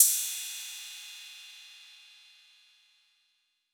Crash 1.wav